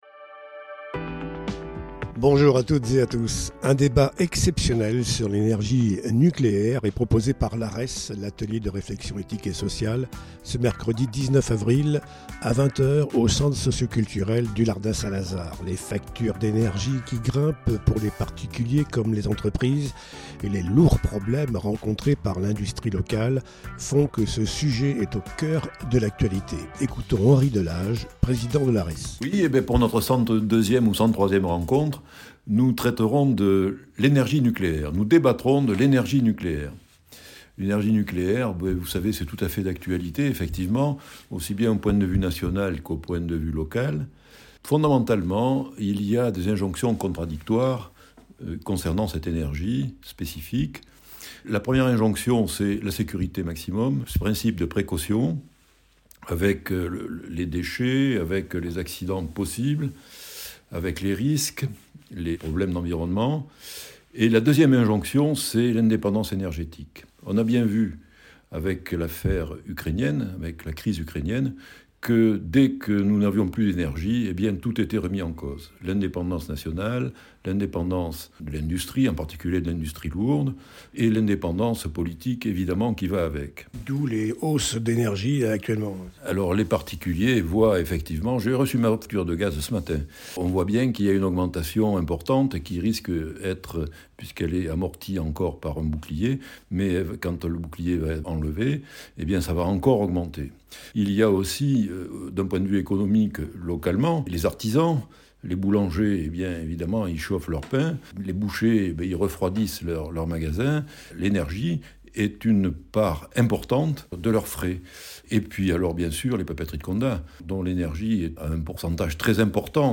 Interview audio